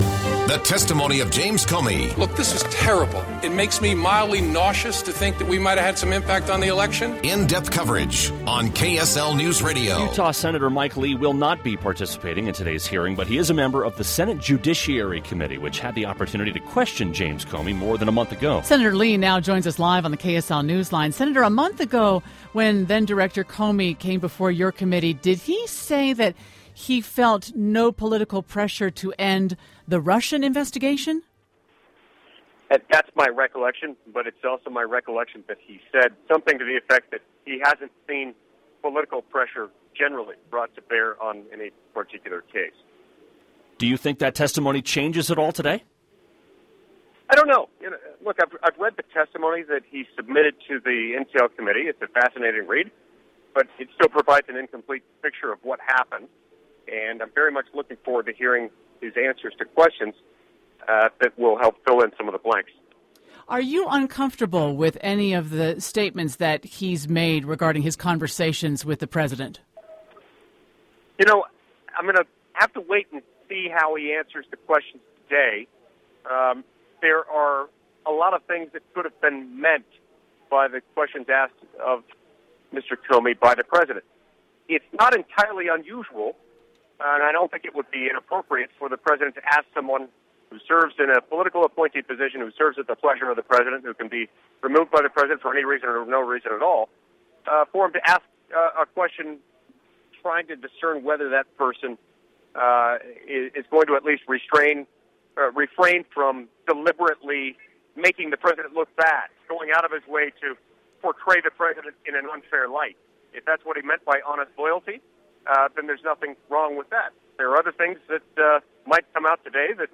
Interview: Mike Lee talks about upcoming Comey testimony